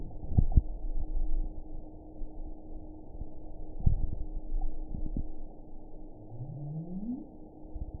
event 921954 date 12/23/24 time 07:47:44 GMT (11 months, 1 week ago) score 7.44 location TSS-AB03 detected by nrw target species NRW annotations +NRW Spectrogram: Frequency (kHz) vs. Time (s) audio not available .wav